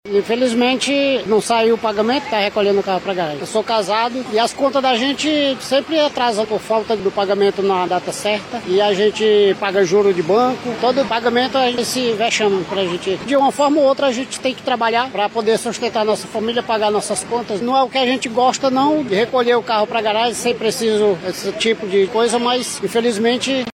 Na avenida Brasil, na zona Oeste da capital amazonense, o motorista de ônibus